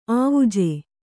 ♪ āvuje